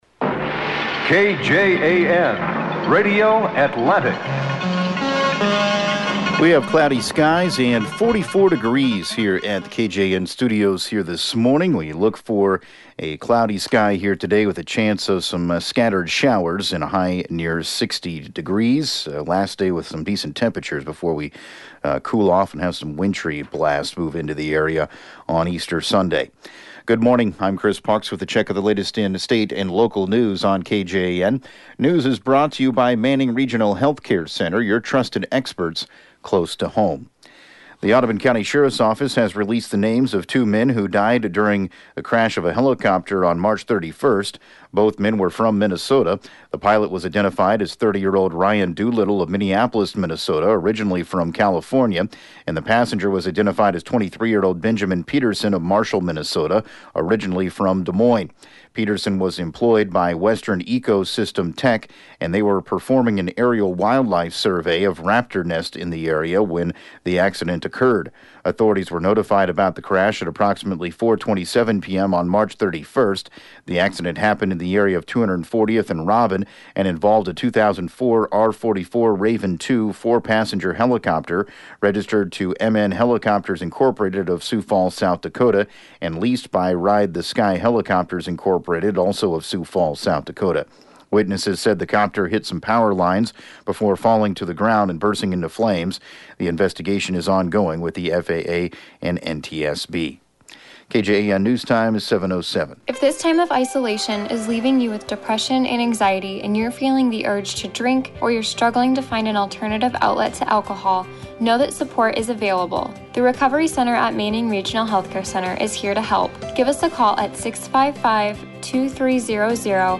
7AM Newscast 04/11/2020